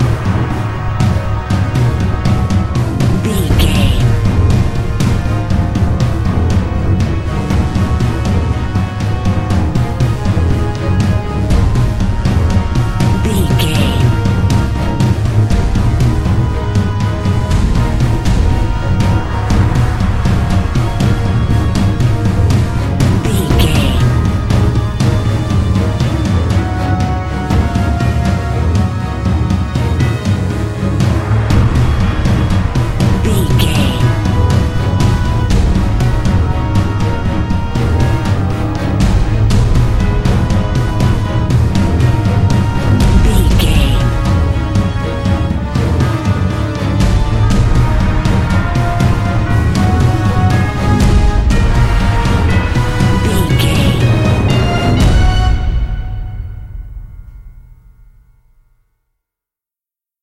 Epic / Action
Aeolian/Minor
E♭
strings
orchestral hybrid
dubstep
aggressive
energetic
intense
bass
synth effects
wobbles
driving drum beat